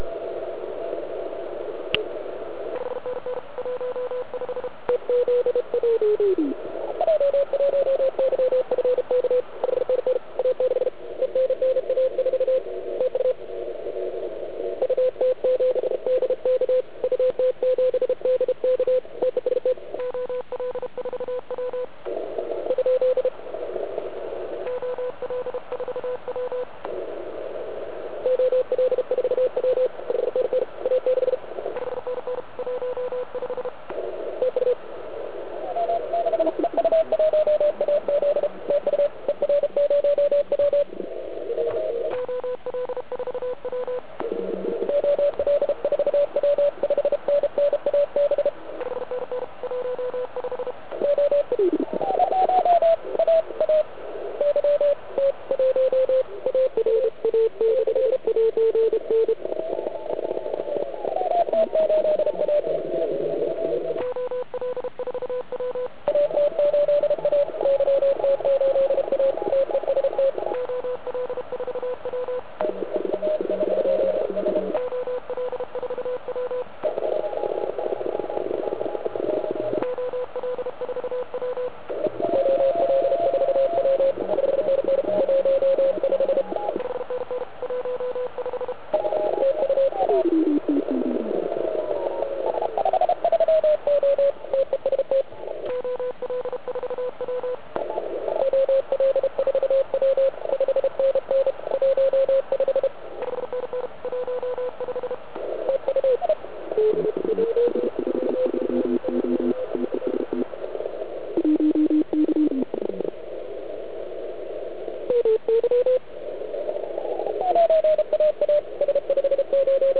Band je klasicky "panelákově zaprskán asi na S9.